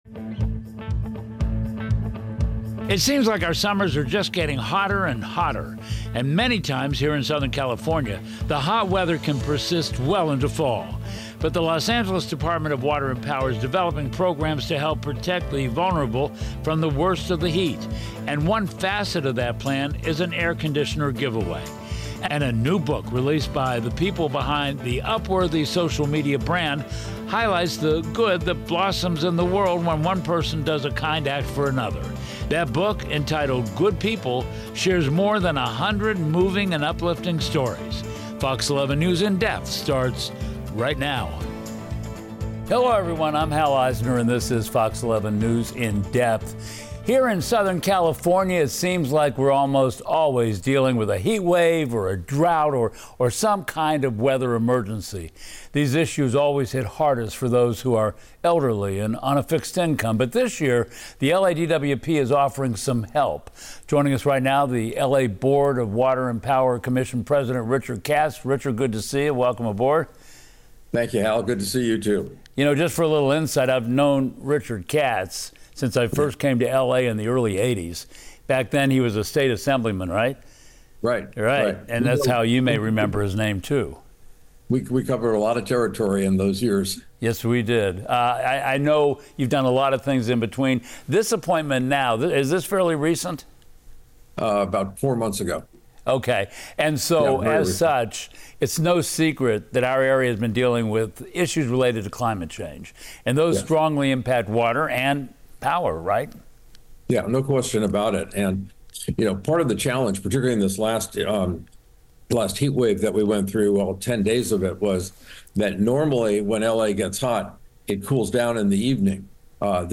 interviews Board of Water and Power Commission President Richard Katz about the challenges the DWP faces due to climate change and how they prepare for hot weather.